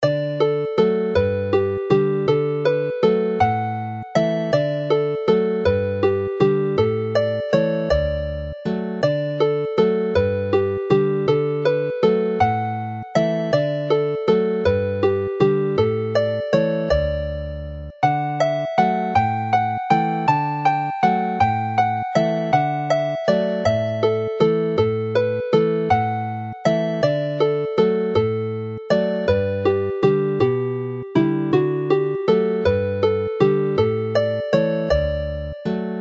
Chwarae'r alaw'n araf
Play the tune slowly